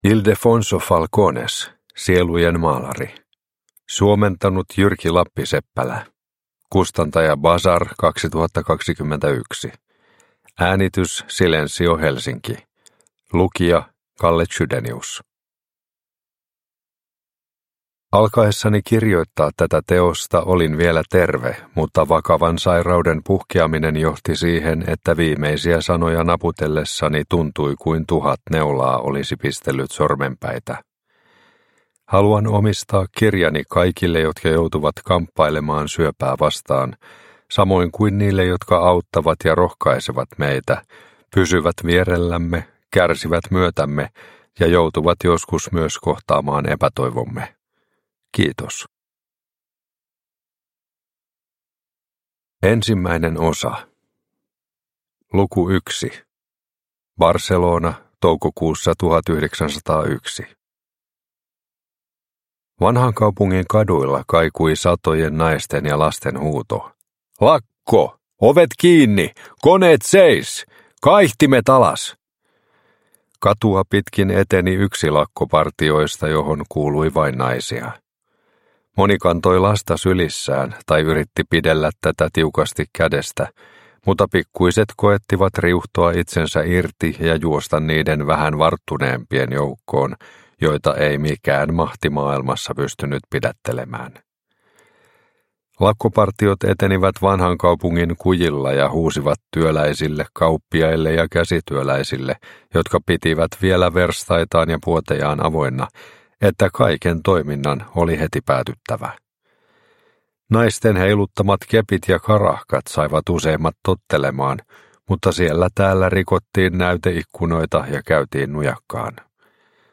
Sielujen maalari – Ljudbok – Laddas ner